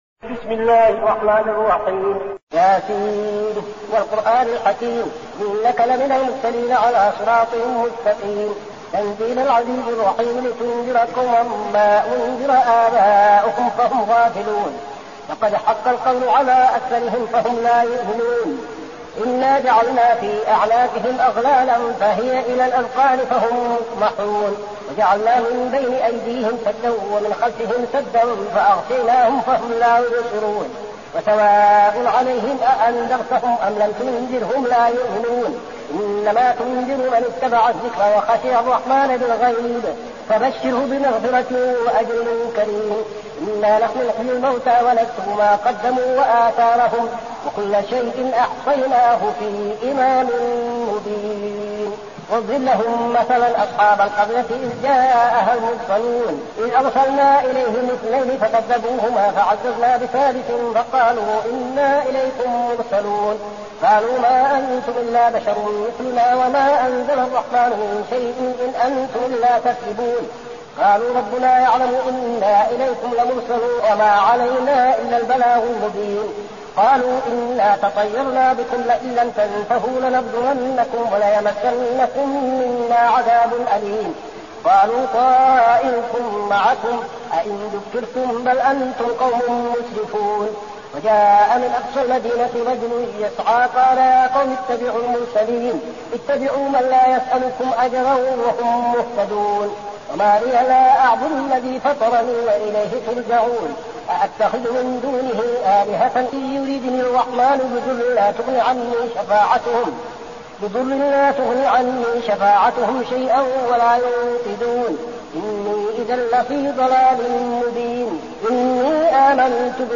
المكان: المسجد النبوي الشيخ: فضيلة الشيخ عبدالعزيز بن صالح فضيلة الشيخ عبدالعزيز بن صالح يس